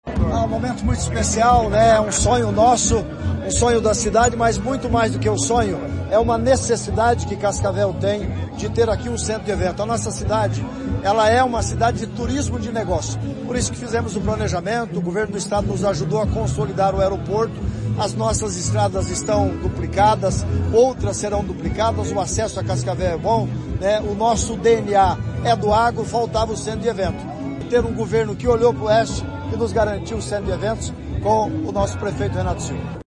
Sonora do secretário do Turismo, Leonaldo Paranhos, sobre a construção do centro de convenções e eventos do Oeste | Governo do Estado do Paraná